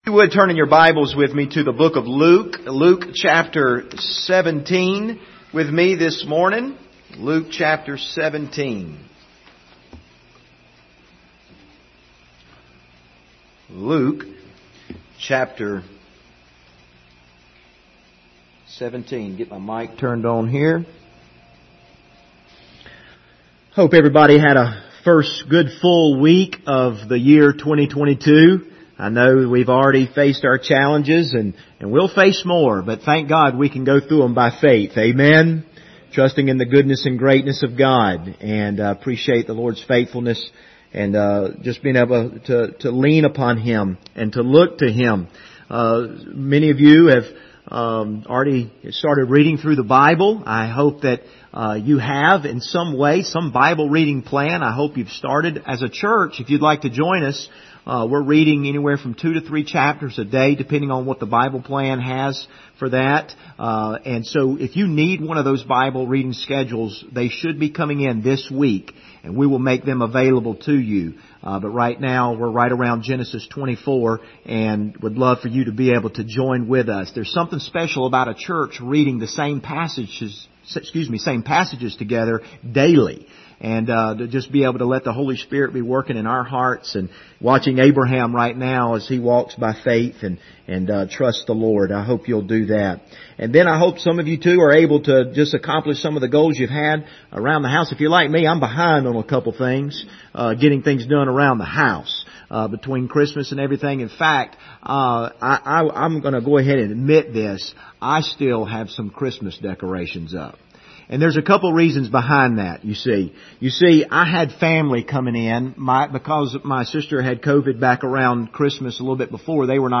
Passage: Luke 17:1-10 Service Type: Sunday Morning